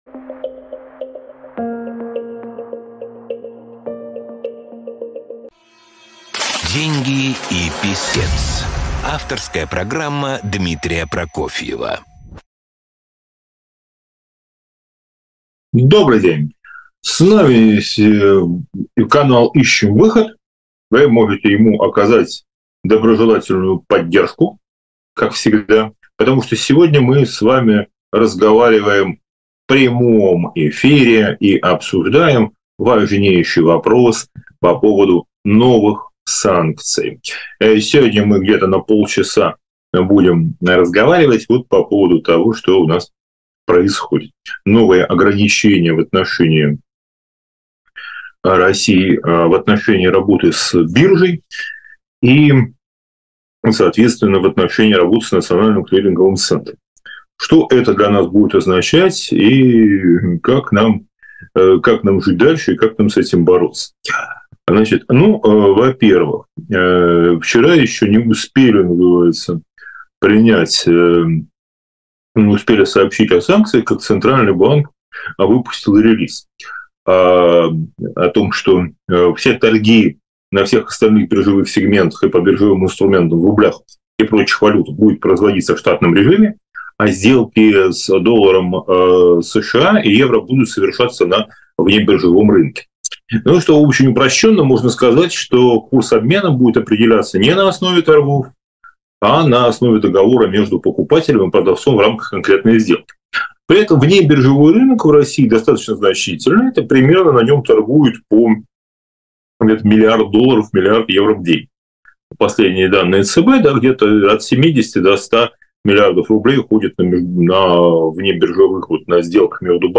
прямой эфир